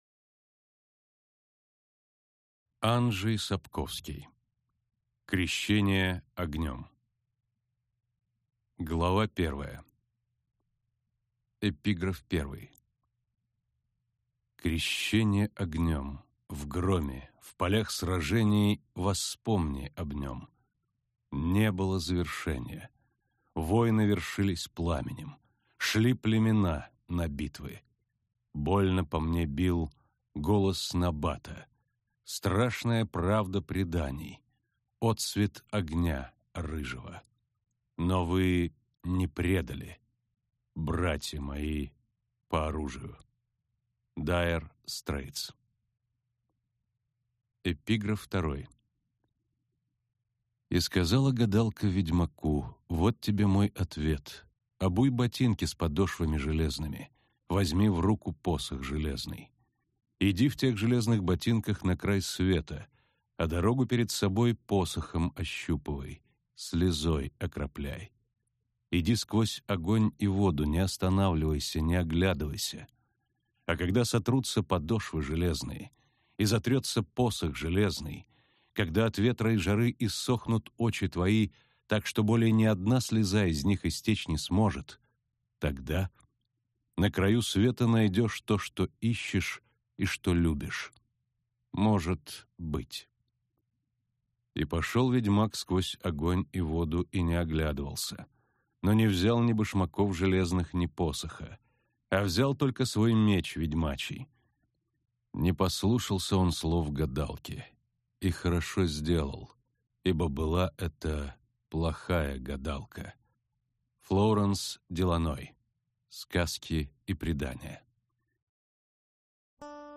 Аудиокнига Крещение огнем | Библиотека аудиокниг